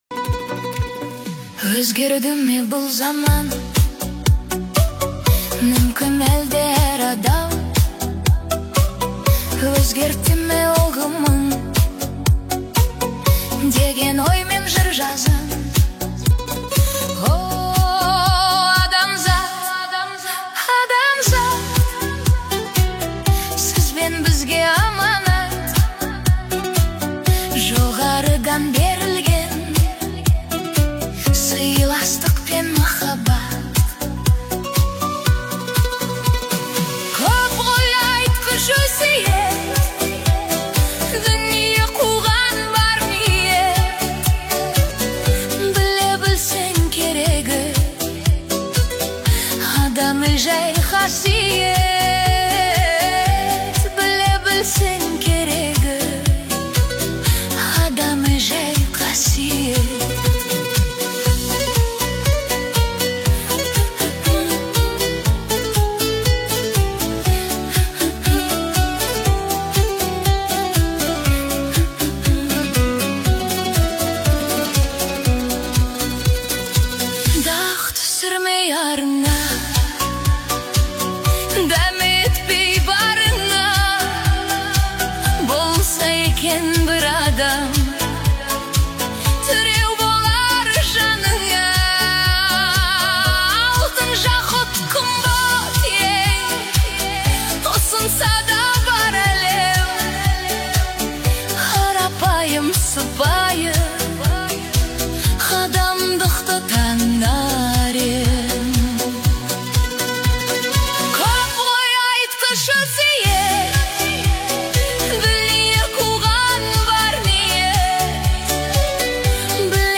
Новая хитовая казахская песня